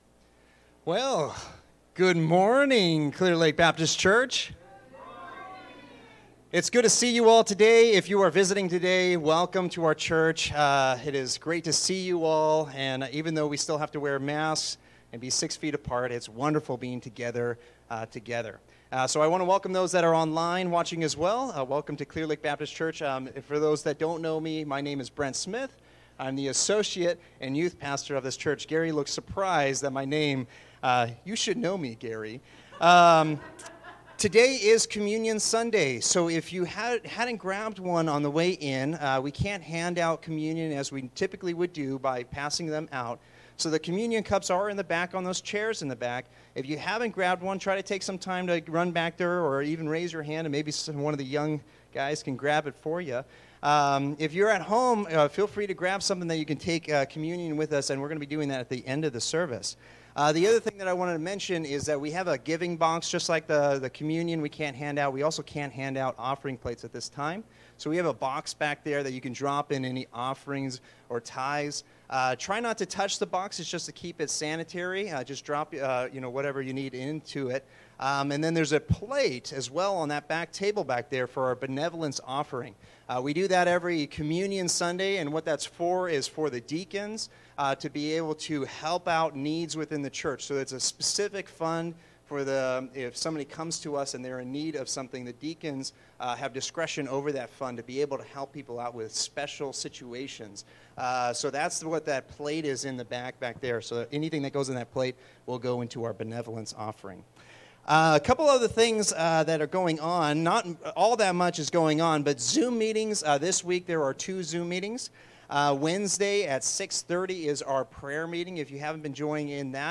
Bible Text: Luke 24:13-33 | Preacher